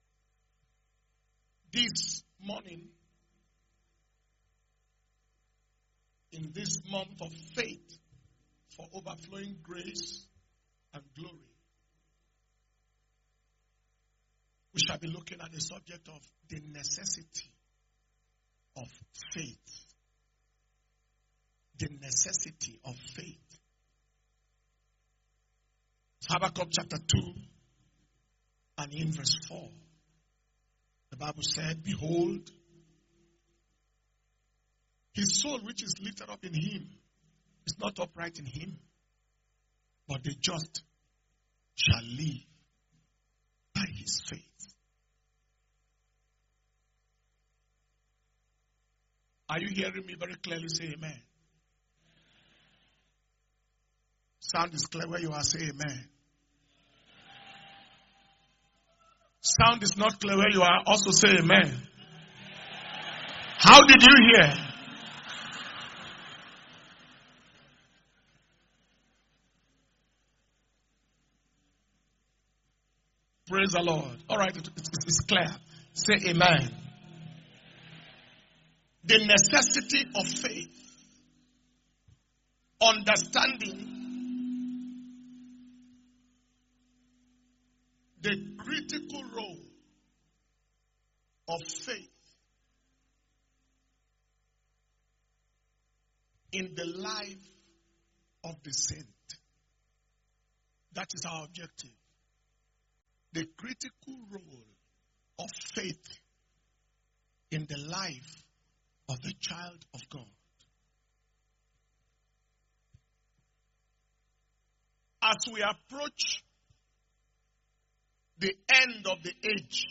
April 2022 Anointing Service